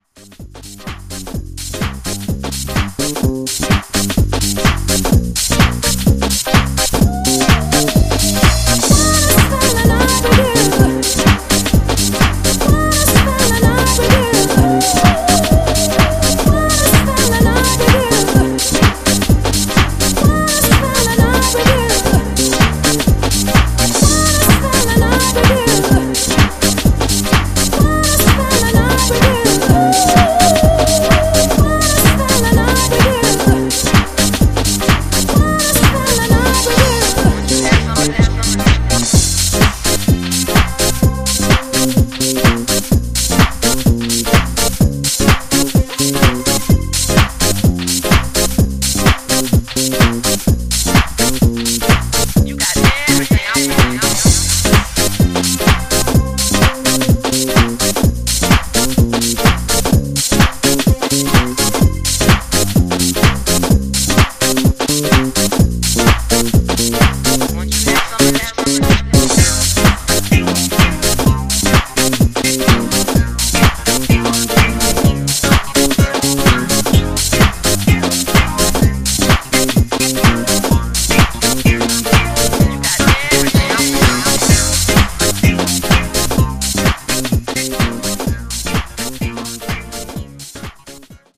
ジャンル(スタイル) HOUSE CLASSIC / UK GARAGE / DEEP HOUSE